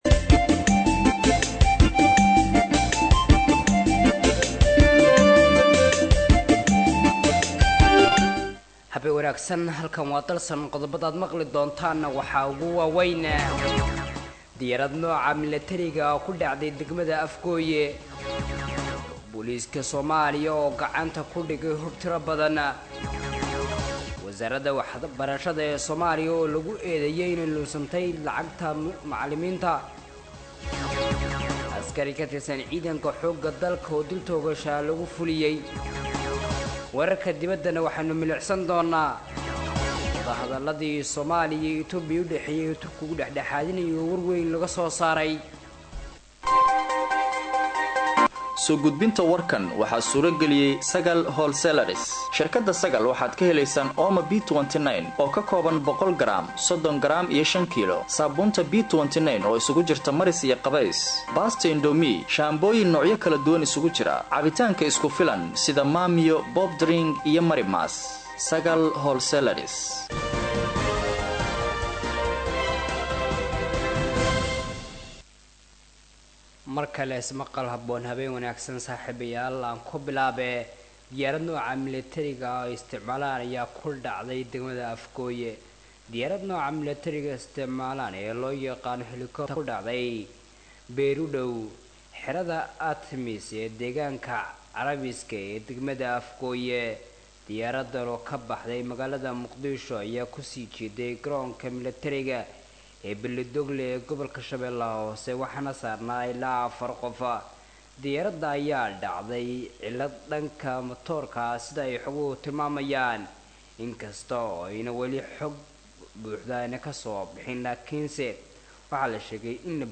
Dhageyso:- Warka Habeen Ee Radio Dalsan 19/09/2024